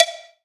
1ST-COW   -L.wav